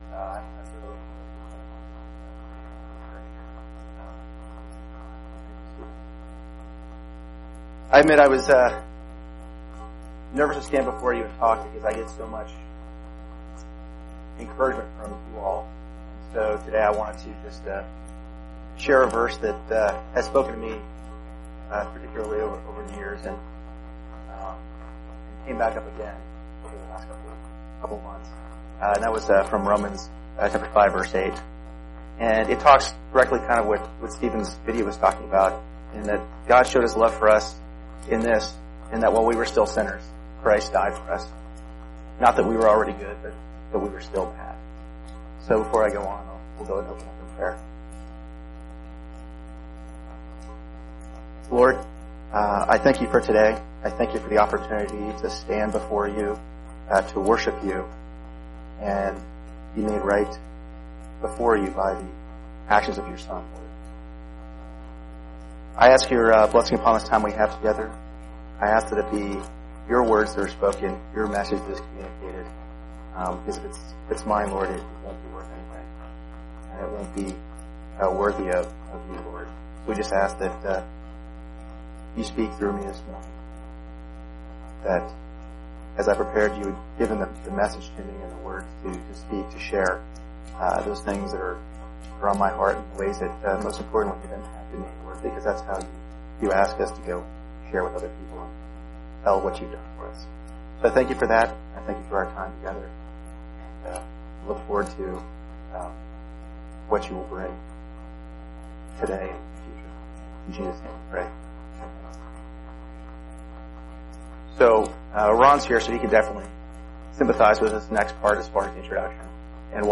While the noise remains, it’s clear during our morning worship in person, please join Sunday at 10AM for worship!
October 21, 2018 Sermons, Uncategorized